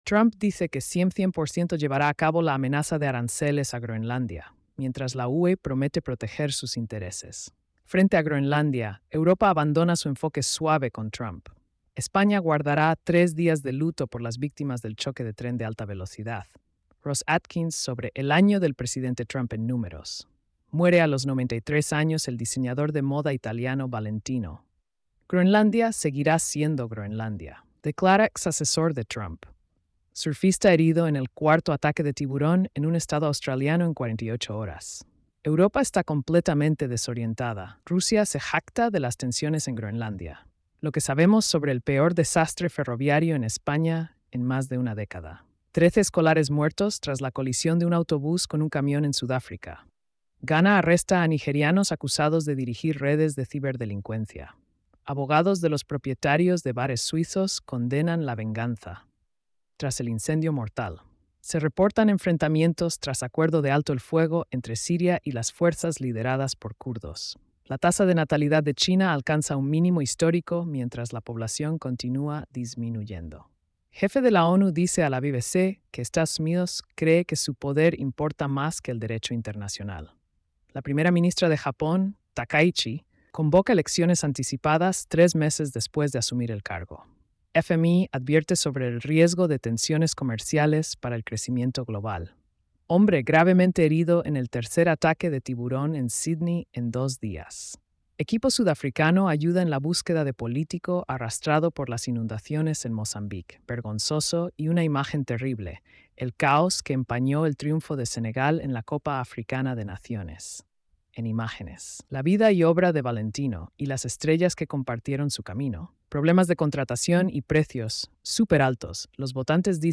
🎧 Resumen de noticias diarias. |